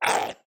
Minecraft Version Minecraft Version snapshot Latest Release | Latest Snapshot snapshot / assets / minecraft / sounds / mob / strider / hurt2.ogg Compare With Compare With Latest Release | Latest Snapshot
hurt2.ogg